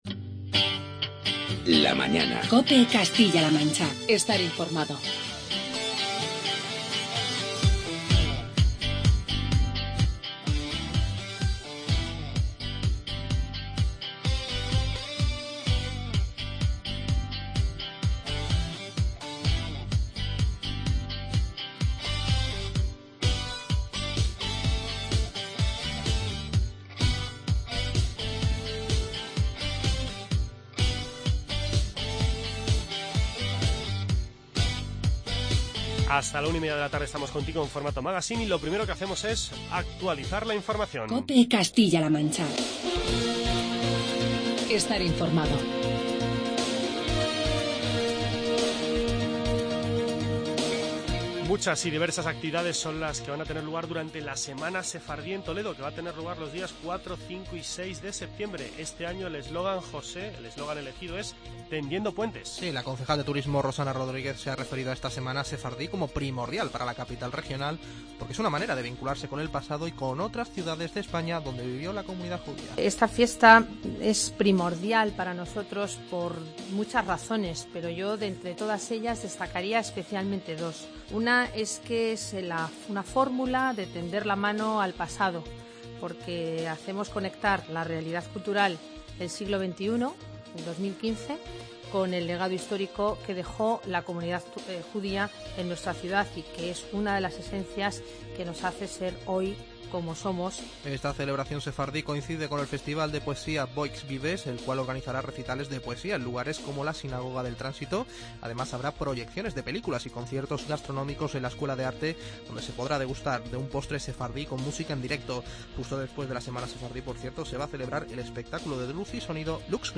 Hoy con el alcalde de Quer, José Miguel Benítez, y con el primer edil de Villar de Cañas, José Manuel Sáiz